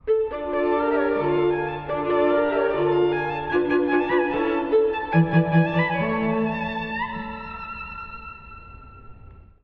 第4楽章 暗闇から光へと至る終章
緊張感のある短調で始まりますが、終盤で一転、長調に転じて幕を閉じます。
強弱・緩急に富んだ内容で、聴きごたえたっぷりです！